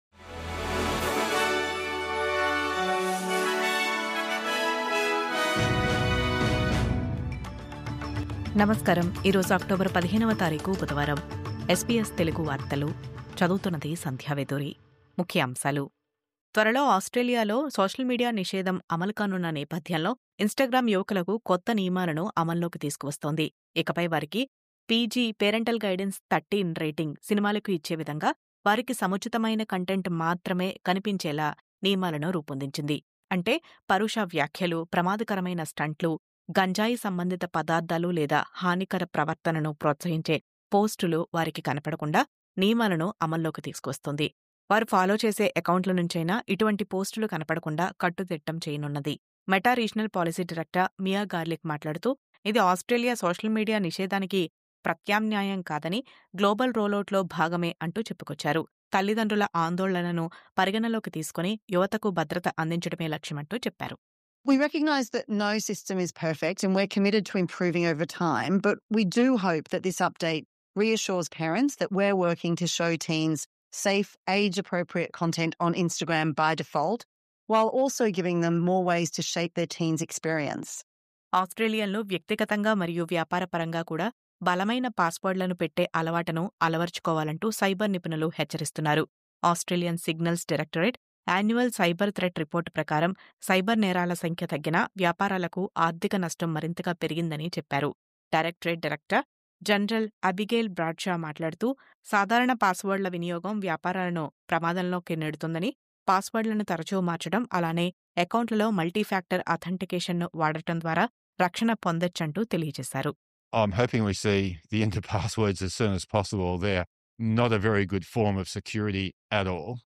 News update: కస్టడీలో ఆదిమ తెగల మరణాలు రికార్డు స్థాయిలో..